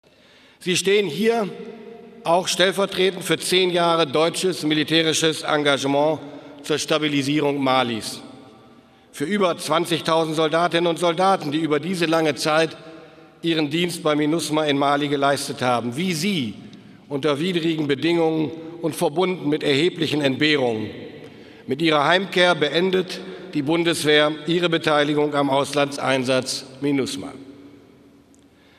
231216 O-Ton Pistorius RA MALI 2v4